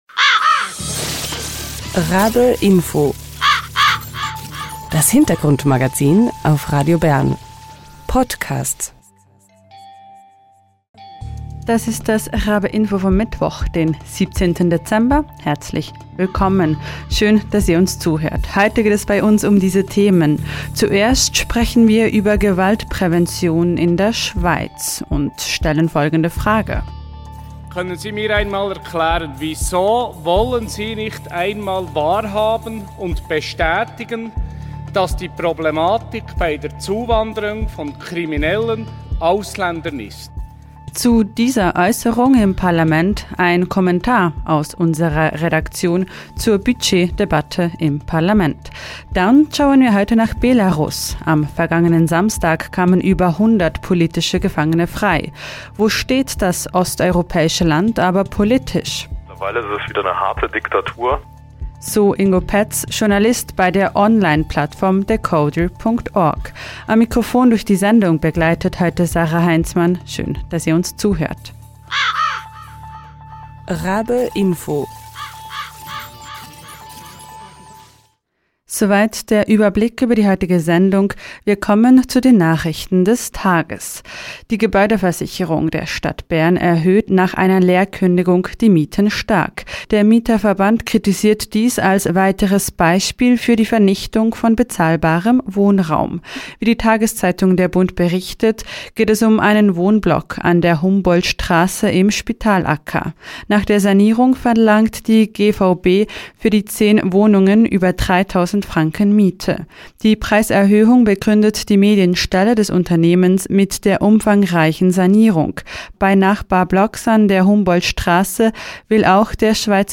In der Budgetdebatte im Parlament wird deutlich, wo gespart wird, und wo nicht. Ein Kommentar aus unserer Redaktion zur Frage, welchen Stellenwert Prävention künftig noch haben soll.